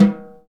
Index of /90_sSampleCDs/Roland LCDP03 Orchestral Perc/SNR_Orch Snares/SNR_Dry Snares
SNR SNAREL0I.wav